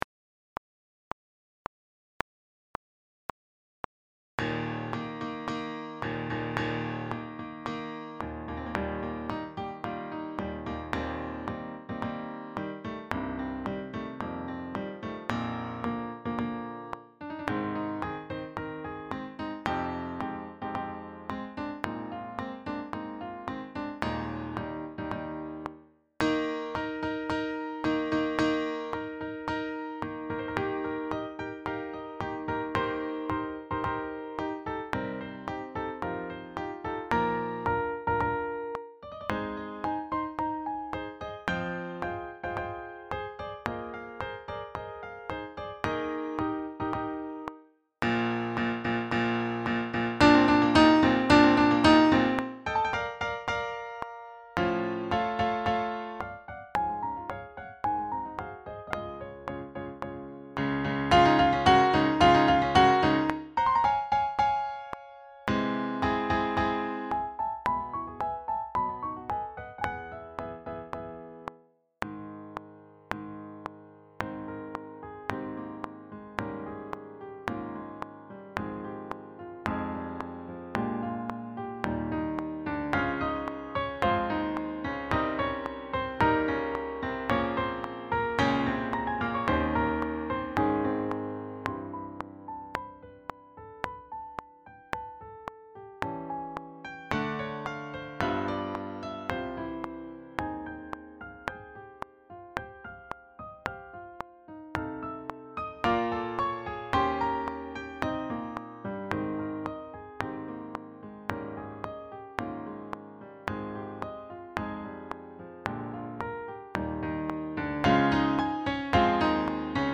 Sax Choir
Written in celebration of his own 25th Wedding Anniversary, Grieg's piano piece "Hochzeitstag auf Troldhaugen" (literally "Wedding Anniversary at Troldhaugen" but often known as "Wedding Day at Troldhaugen") is a joyful march and interlude with a simple yet memorable theme.
This arrangement is a simplification of the piano score but still leaves plenty of work to do for all the saxes.
Backing track
163-4-wedding-day-at-troldhaugen-backing-track.mp3